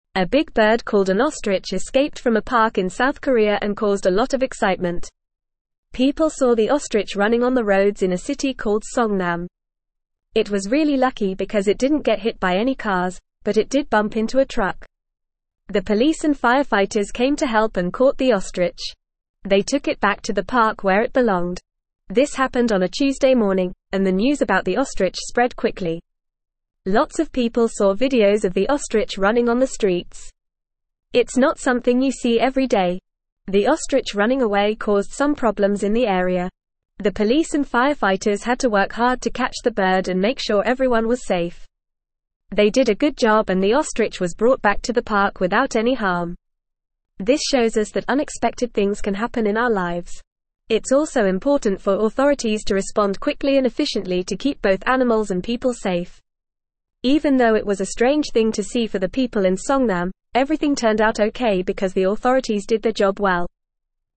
Normal
English-Newsroom-Upper-Intermediate-FAST-Reading-Escaped-ostrich-causes-chaos-on-roads-in-South-Korea.mp3